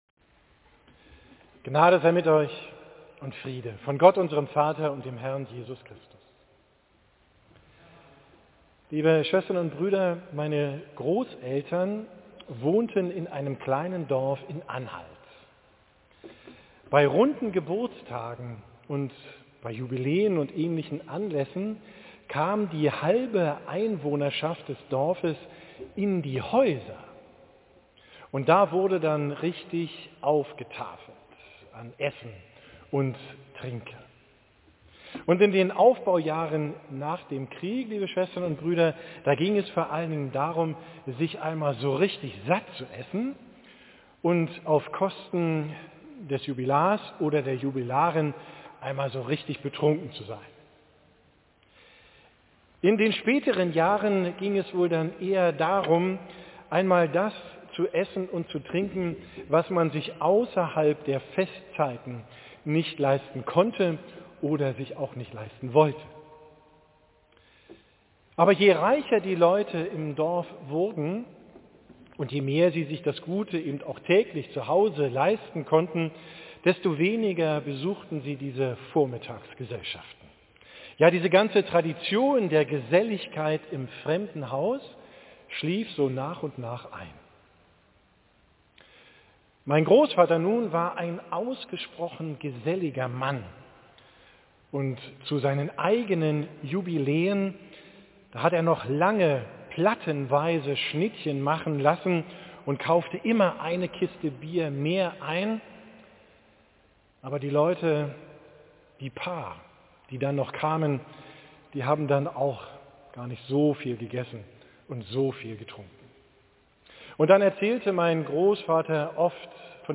Predigt vom 2. Sonntag nach Trinitatis